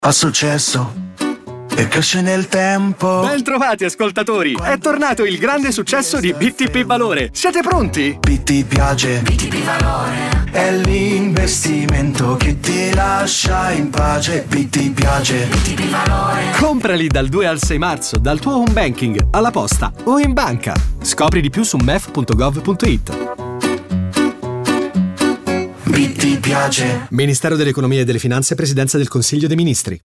Lo spot radio
Caratterizza lo spot un motivo musicale dal ritmo orecchiabile, pensato per rendere il messaggio immediatamente riconoscibile e facile da ricordare.